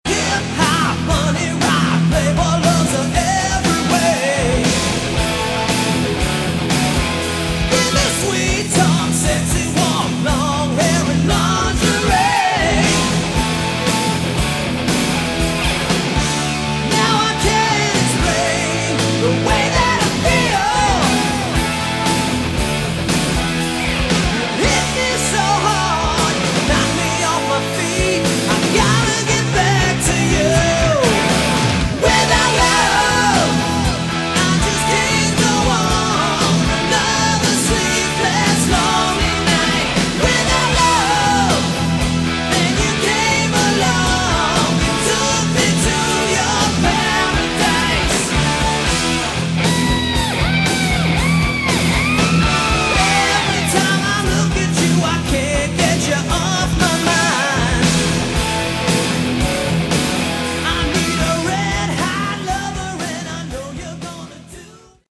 Category: Hard Rock
Vocals
All Guitars
Bass
Keyboards
Drums & Backing Vocals